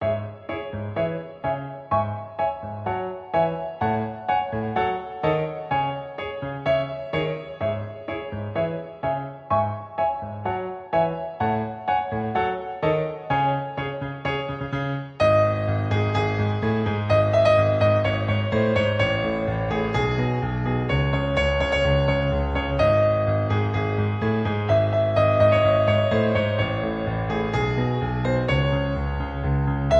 • Key: C# Minor
• Genre: Pop